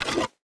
Pick Up Wood A.wav